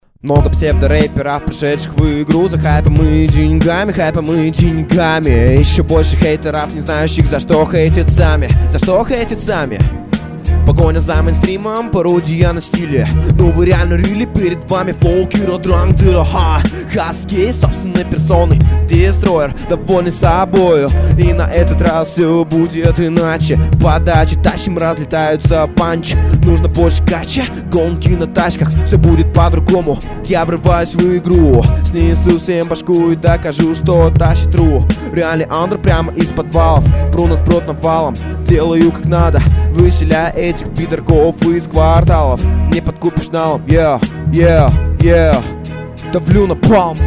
Хорошо разнообразил читка, но опыта пока не хватает.